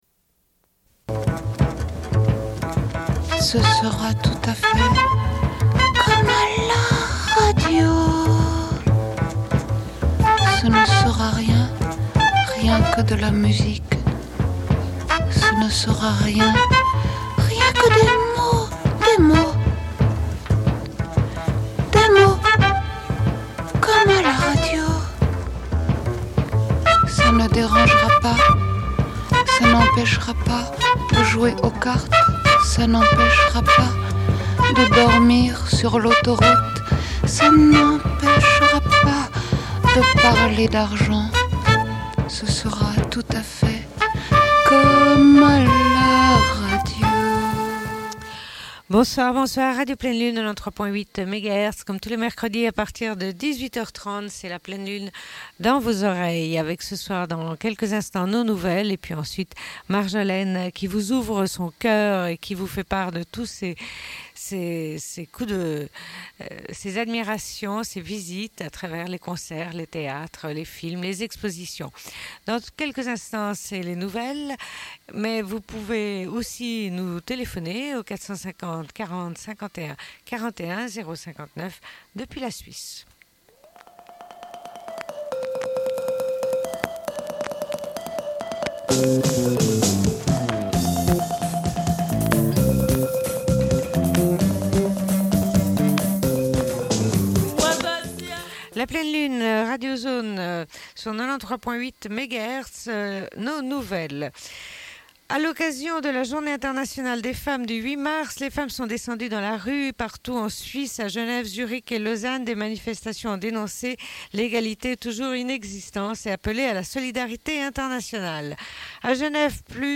Bulletin d'information de Radio Pleine Lune du 09.12.1998 - Archives contestataires
Une cassette audio, face B